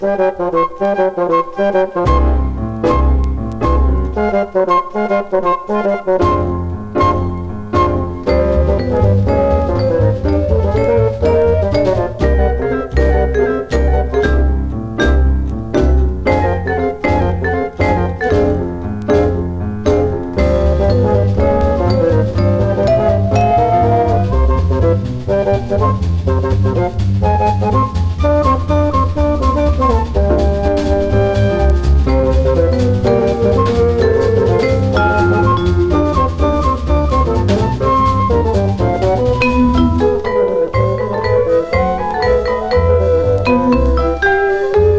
Jazz, Bop, Big Band　USA　12inchレコード　33rpm　Mono